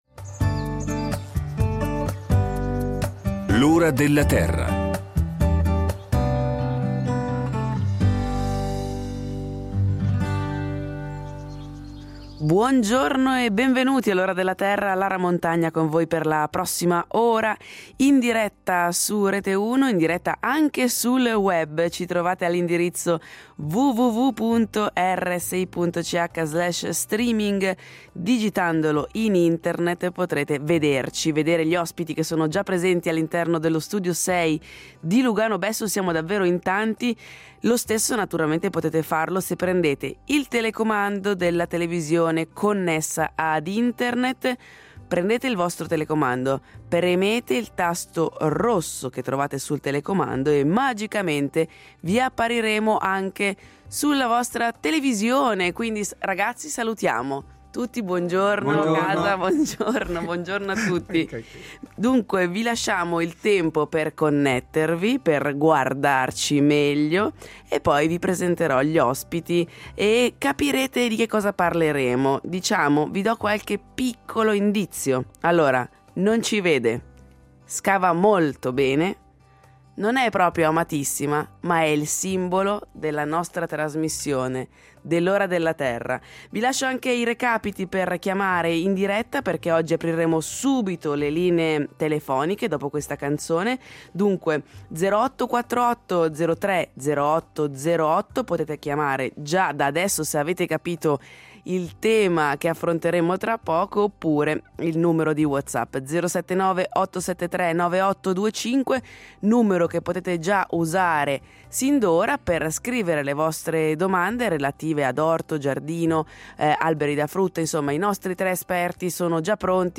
A seguire i tre esperti de L’Ora della Terra risponderanno alle domande del pubblico da casa, le linee telefoniche saranno aperte dalle 9:30.